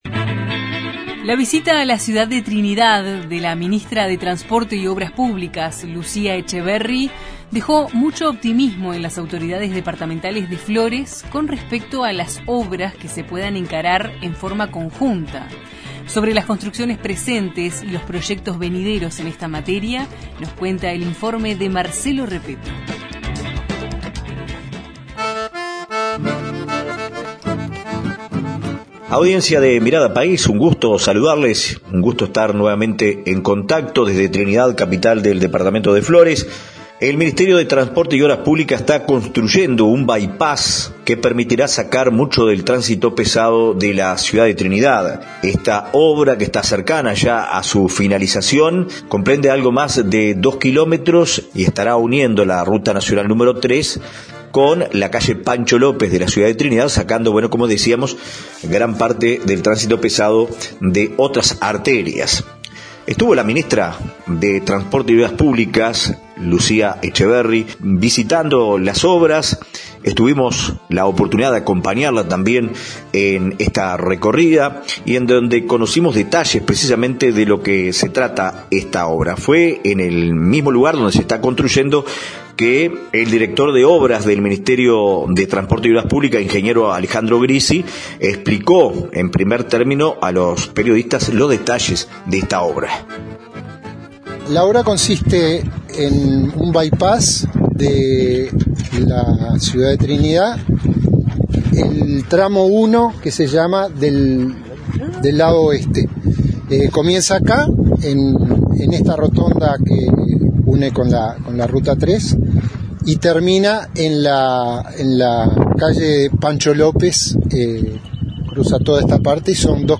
Los informes de nuestros corresponsales en Durazno, Flores y Paysandú.